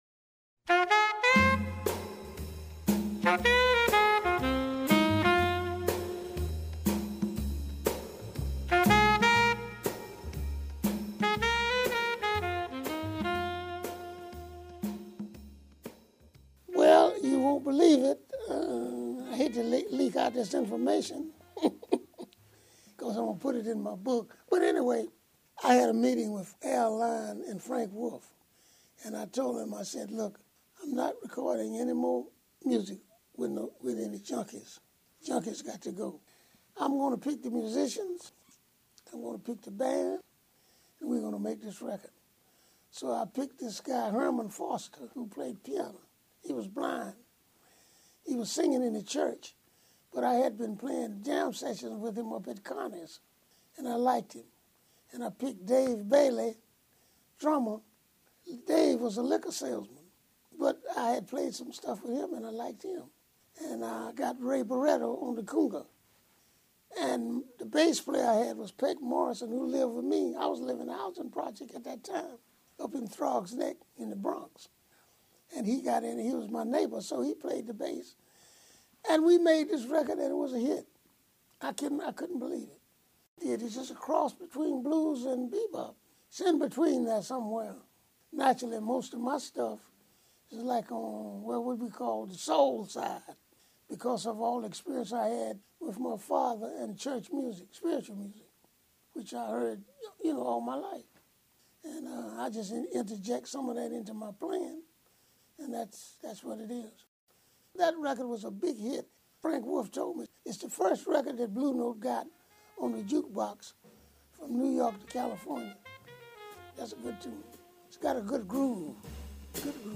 In this excerpt from the podcast, Donaldson talks about how that 1958 Blue Note recording came together.